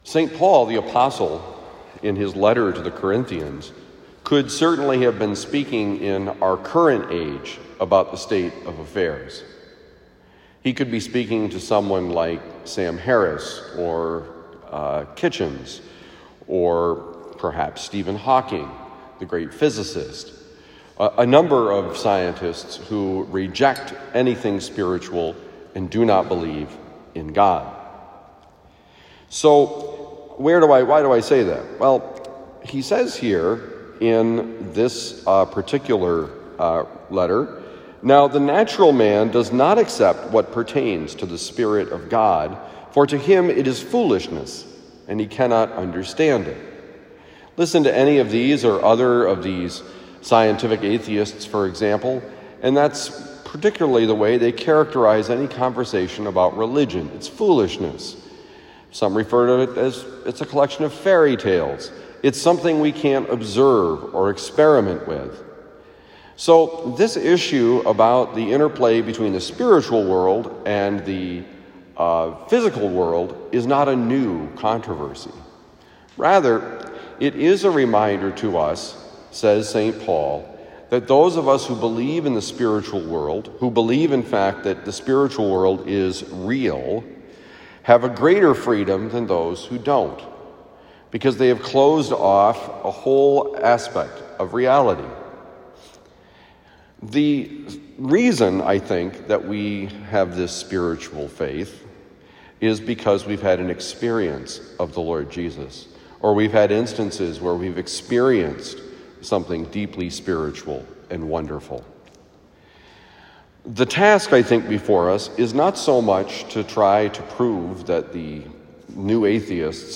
All of Reality: The world is spiritual and natural: Homily for Tuesday, August 30, 2022
Given at Christian Brothers College High School, Town and Country, Missouri.